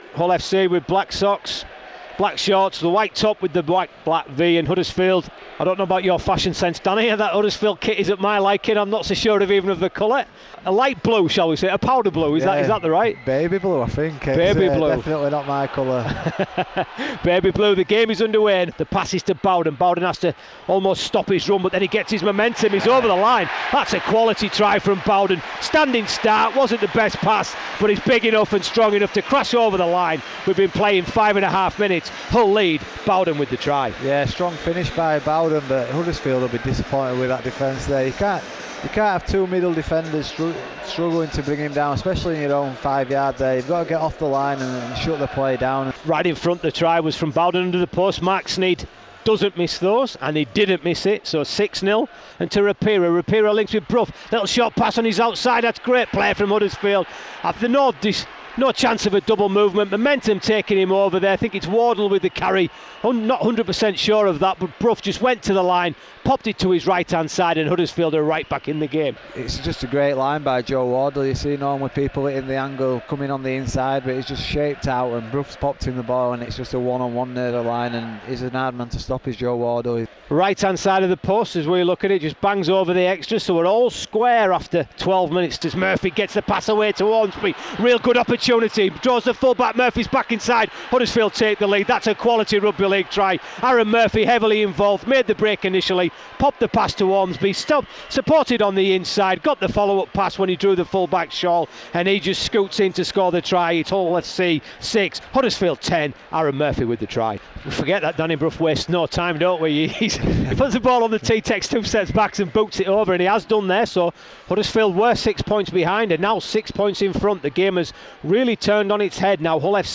Highlights of Radio Yorkshire's commentary of the Round 10 game between Hull FC and Huddersfield Giants which saw the Airlie Birds win 37-20 over the Giants.